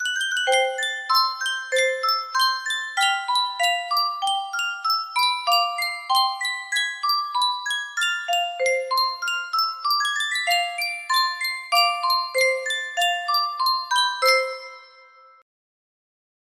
Sankyo Music Box - IGSRDAWMSAL 6G music box melody
Full range 60